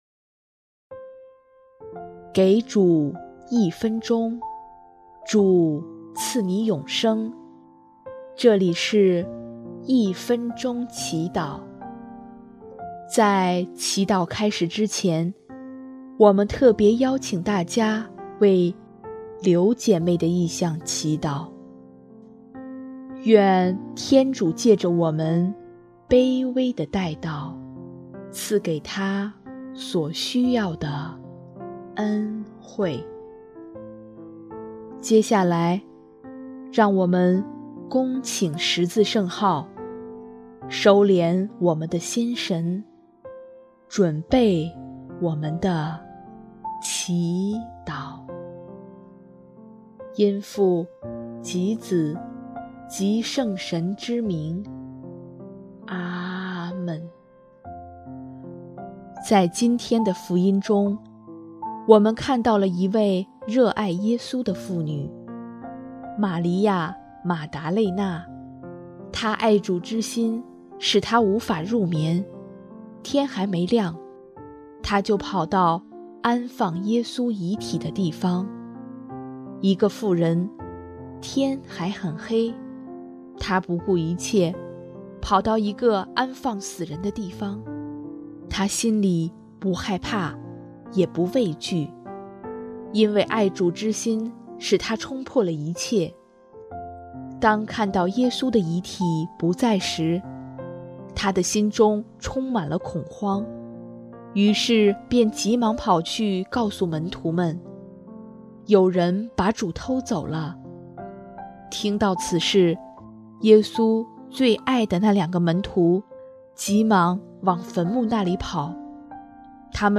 音乐：第一届华语圣歌大赛参赛歌曲《主你了解我》